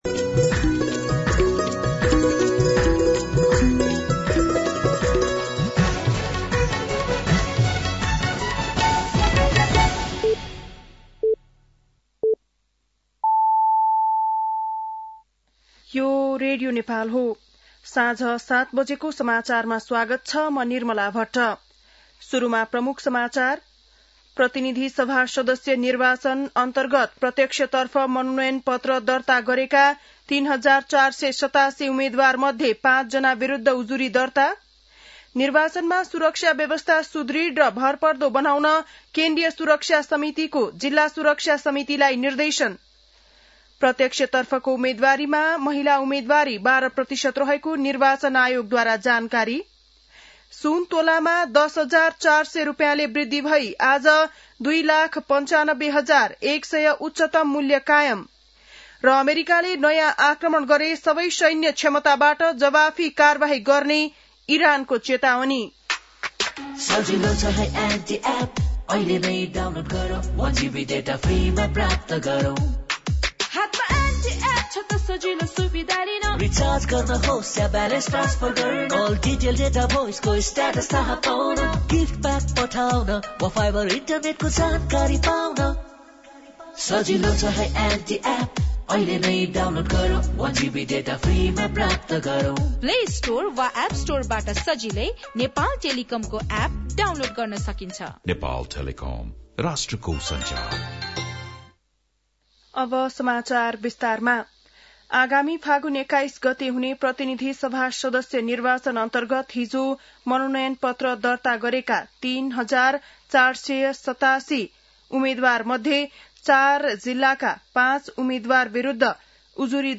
बेलुकी ७ बजेको नेपाली समाचार : ७ माघ , २०८२